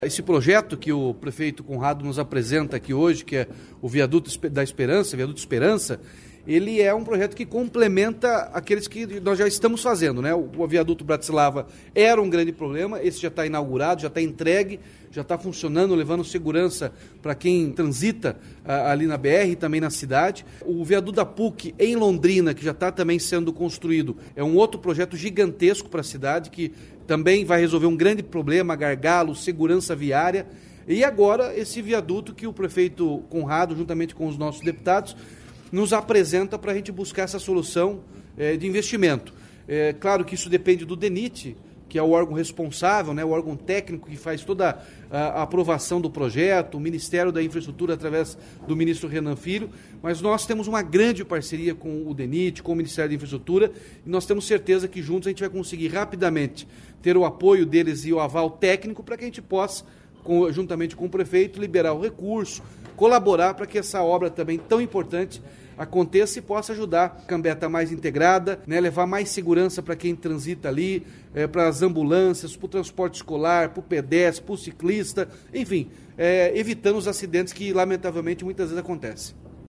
Sonora do governador Ratinho Junior sobre o projeto executivo do Viaduto Esperança